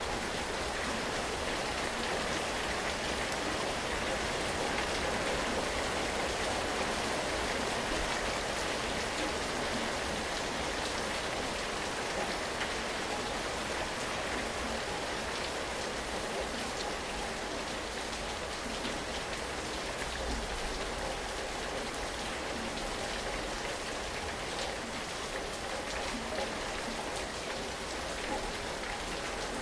大雨
描述：大雨下降。
Tag: 暴风 暴雨 气象 性质 现场录音